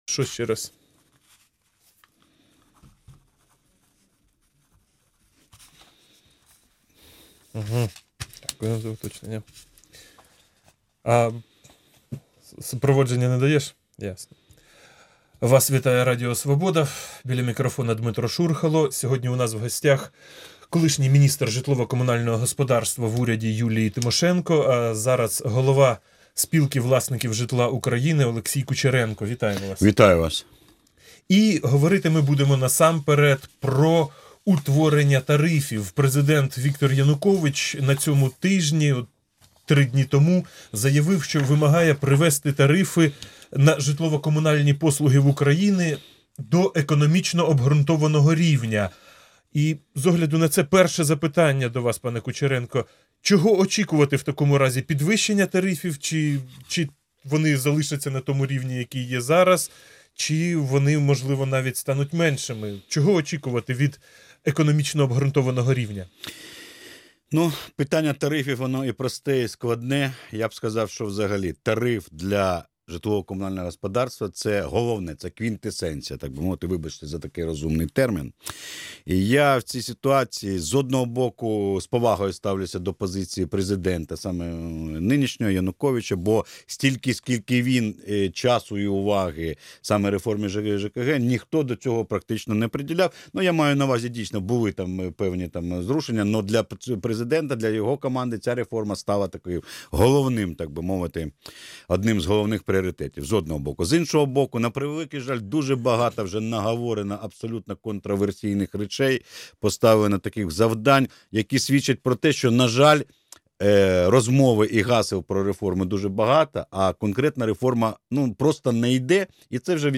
Інтерв’ю з Олексієм Кучеренко